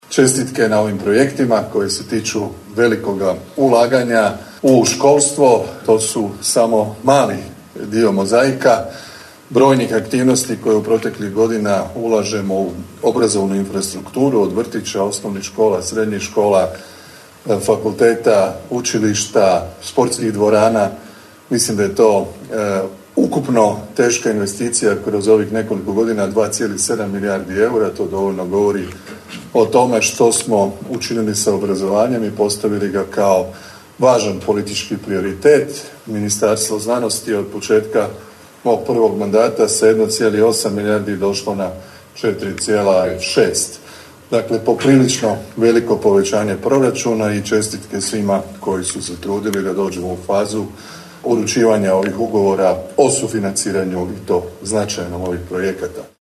Prije početka sastanka u dvorani Arcadia uručeni su Ugovori za nadogradnju i izgradnju škola i Ugovori iz programa modernizacijskog fonda. Točno na vrijeme prije uručivanja Ugovora prisutnima se obratio premijer Vlade RH Andrej Plenković zahvalivši se svim županima, ministrici zaštite okoliša i zelene tranzicije Mariji Vučković, ministru znanosti, obrazovanja i mladih Radovanu Fuchsu kao i gradonačelniku Daruvara Damiru Lneničeku